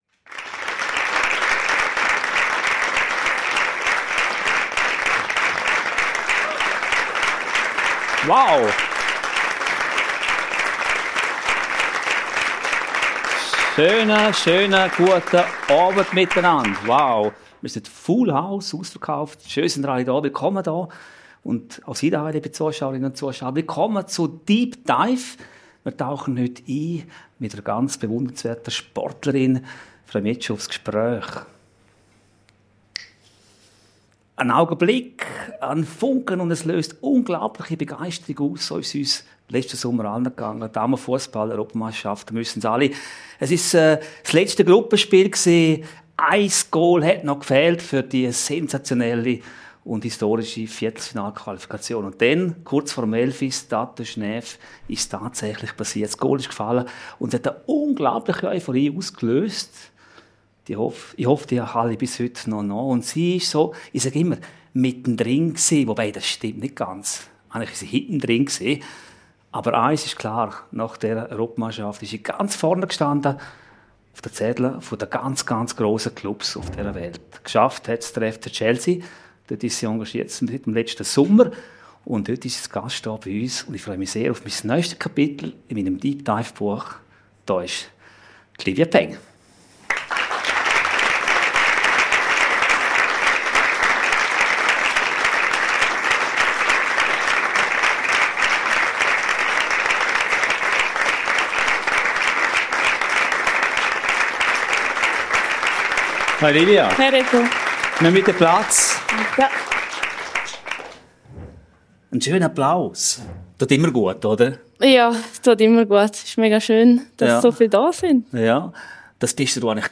Es geht um das Alleinsein im Tor, um Konzentration in entscheidenden Momenten, um Selbstvertrauen nach Fehlern – und darum, wie man auf höchstem Niveau Ruhe bewahrt, wenn der Druck von aussen steigt. Ein Gespräch über Sport hinaus: präzise, reflektiert, ehrlich.